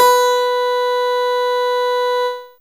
HChordBB4.wav